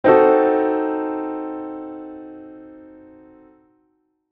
That's the kind of chord that, played by itself, sounds like the villain in a silent movie:
daily-Cdim7.mp3